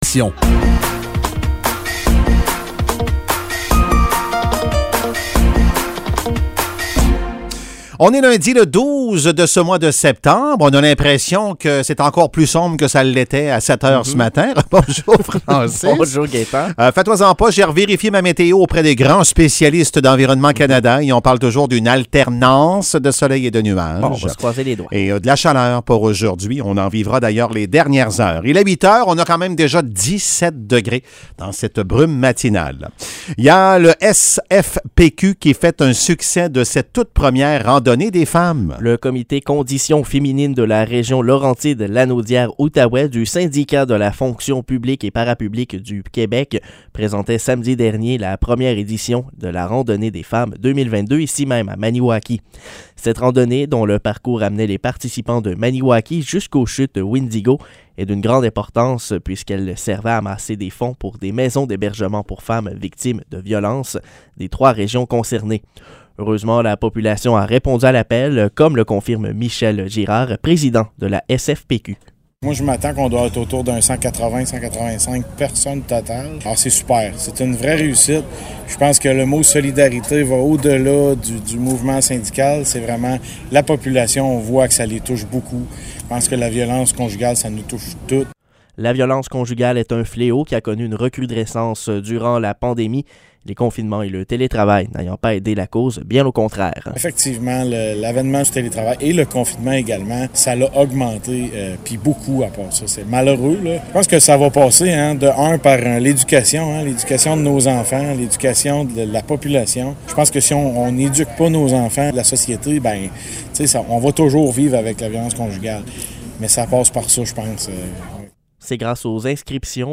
Nouvelles locales - 12 septembre 2022 - 8 h